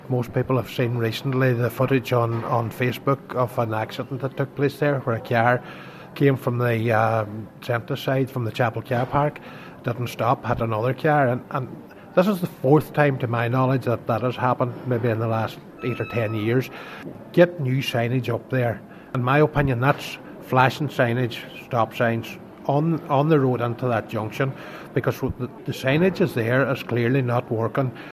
Cllr McBride says at the very least, there needs to flashing warning lights close to the church: